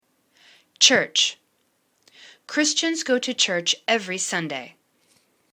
church    /church/    n